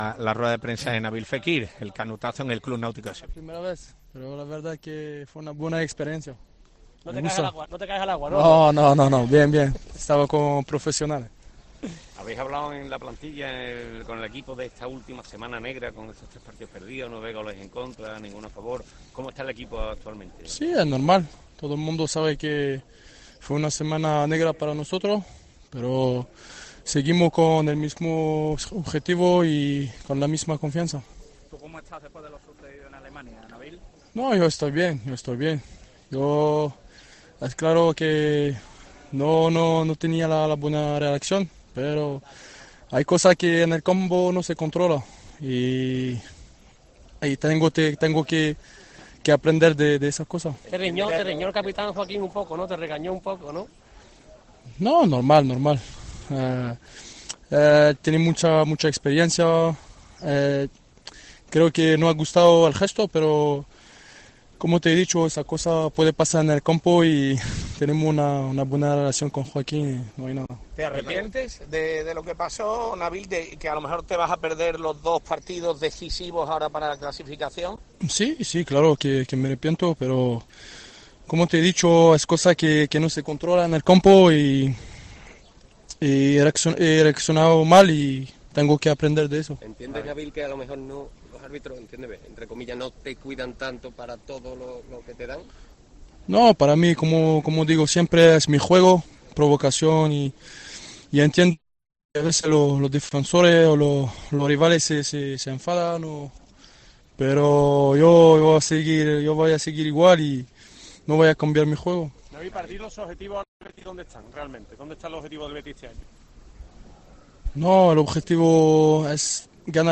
Nabil Fekir, en su comparecencia en el Club Naútico de Sevilla